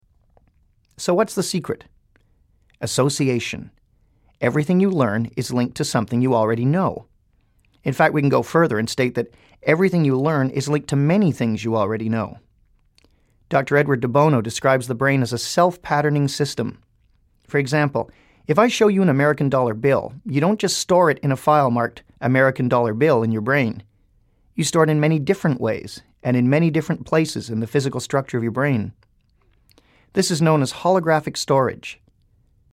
Track 1 is a conscious teaching track.